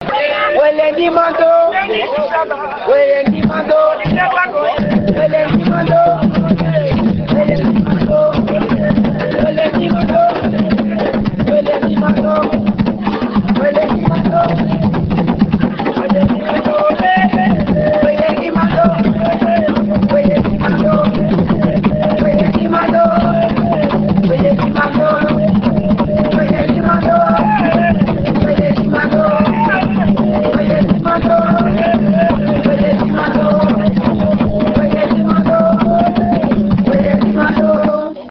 enregistrement durant une levée de deuil (Puubaaka)
danse : awassa (aluku)
Pièce musicale inédite